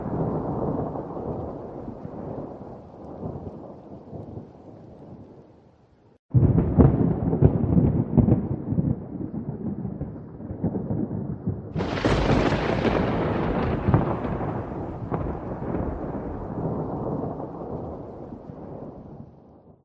Free Ambient sound effect: Fireplace.
Fireplace
406_fireplace.mp3